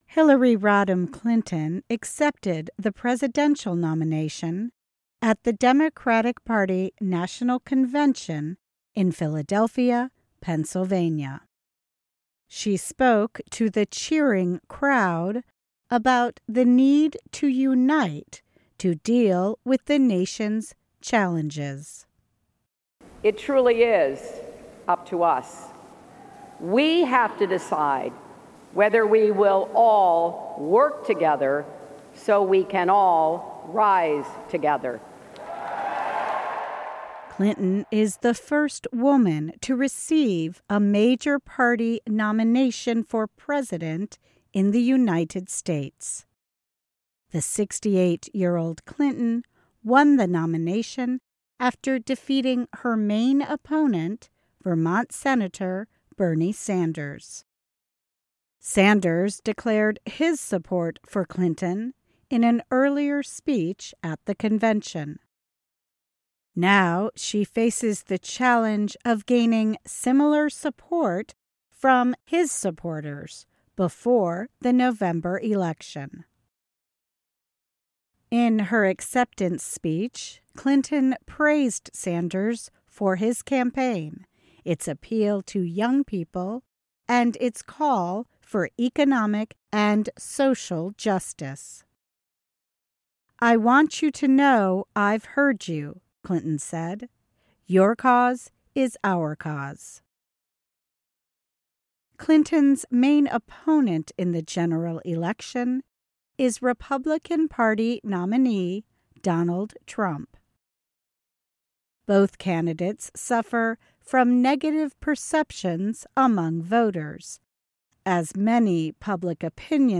Hillary Rodham Clinton accepted the nomination at the Democratic Party National Convention in Philadelphia, Pennsylvania. She spoke to the cheering crowd about the need to unite to deal with the nation’s challenges.